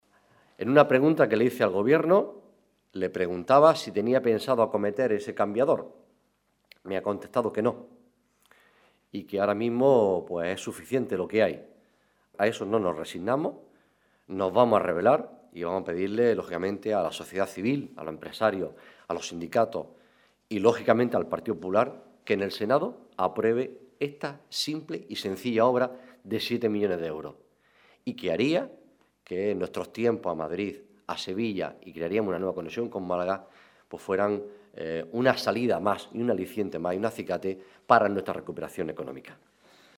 Rueda de prensa que ha ofrecido el senador y secretario de Organización del PSOE de Almería, Juan Carlos Pérez Navas